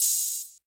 Open Hats
MB Open Hat (4).wav